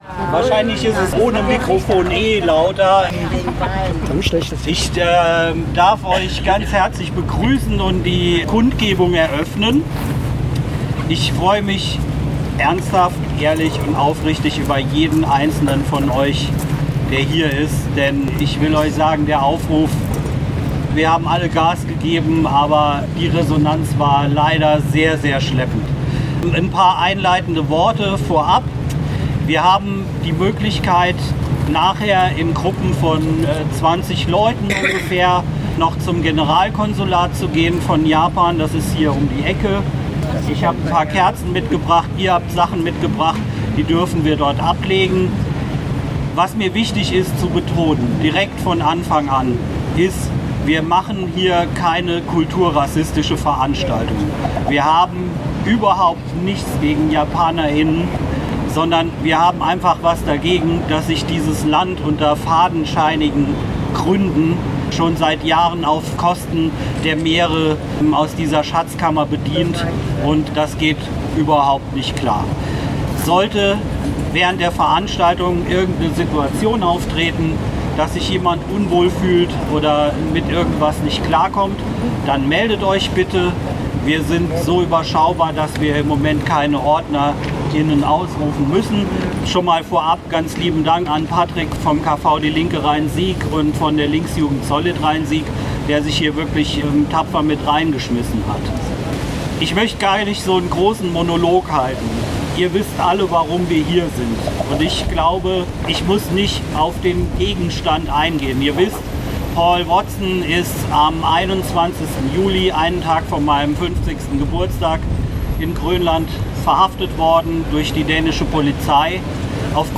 Kundgebung „FREE Paul Watson“ (Audio 1/2)
Die Auftaktrede